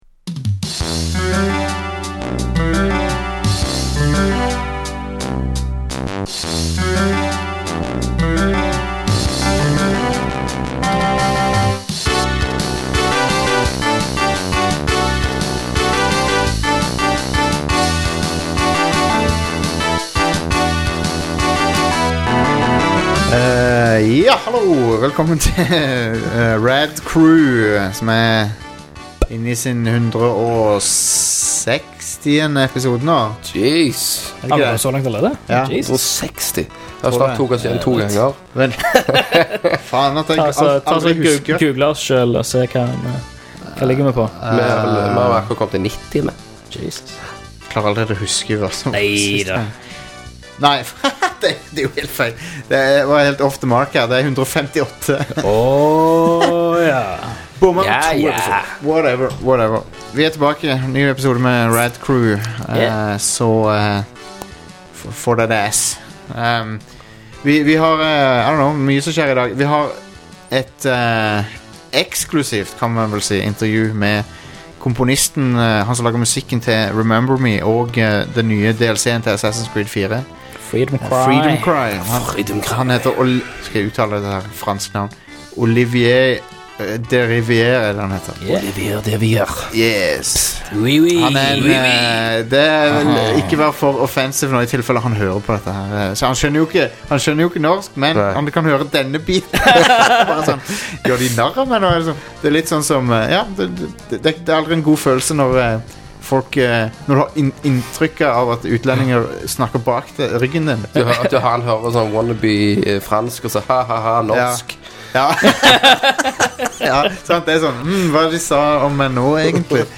Det er duket for en veldig musikalsk episode av Rad Crew!